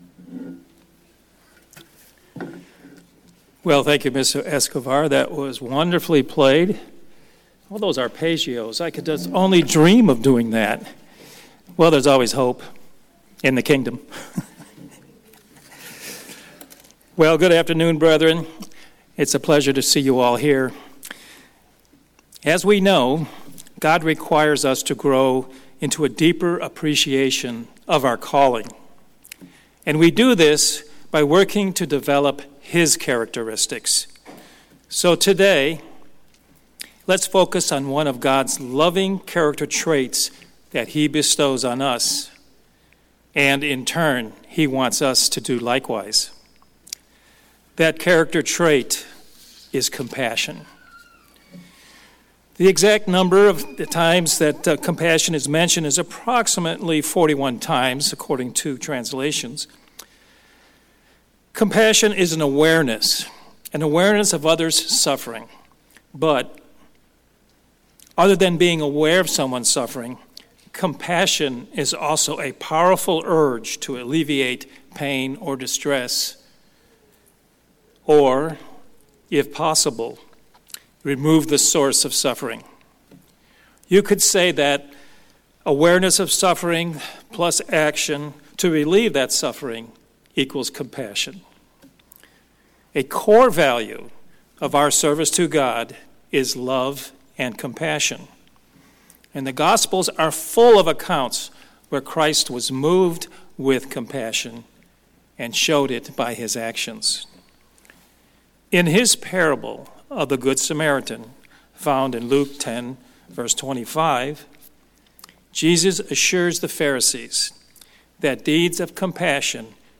Compassion is essential to Christian sanctification. This sermon explores compassion (not just sympathy) toward friends and enemies, examining how God judges both our inward thoughts and outward actions.
Given in Houston, TX